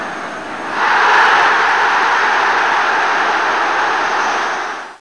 roar.mp3